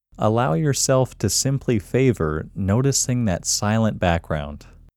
QUIETNESS Male English 12
The-Quietness-Technique-Male-English-12.mp3